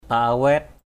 /pa-a-wɛ:t/ (đg.) dứt từng khúc, làm đứt từng khúc = couper en tronçons. paawait njuh jieng klau await F%a=wT W~H j`$ k*~@ a=wT chẻ củi ra thành ba khúc.
paawait.mp3